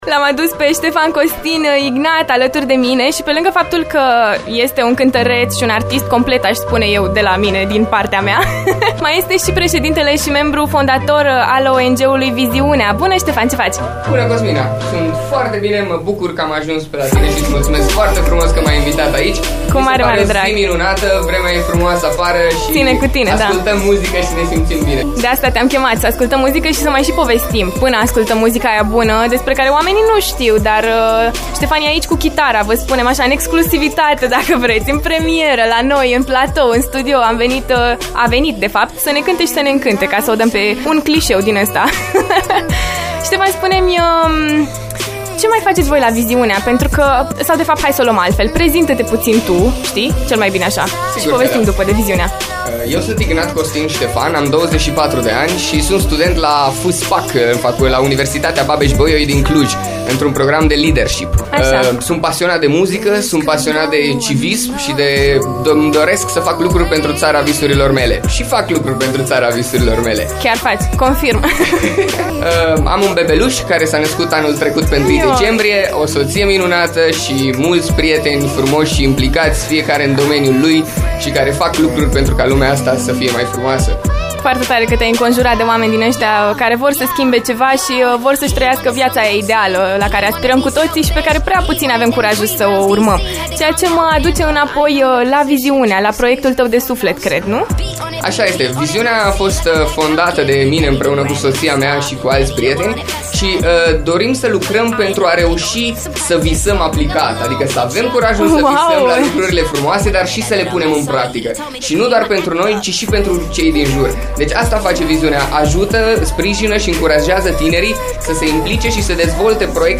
la chitară, în direct la After Morning